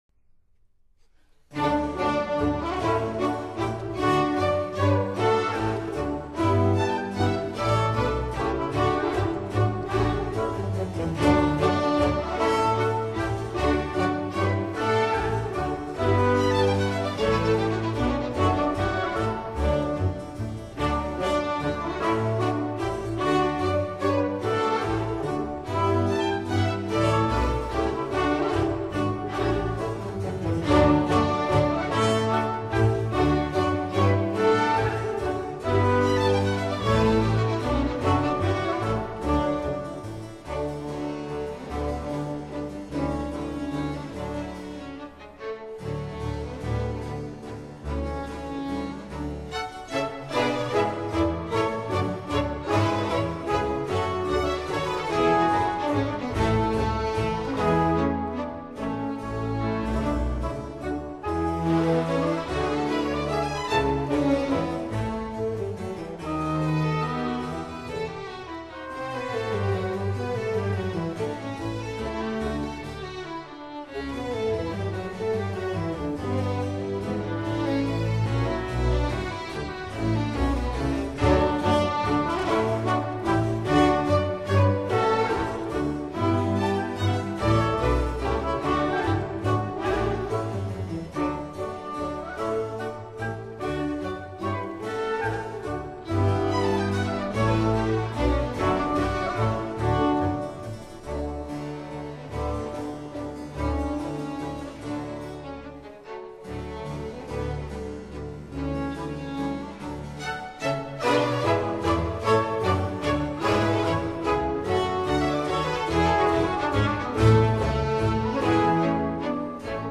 Allegretto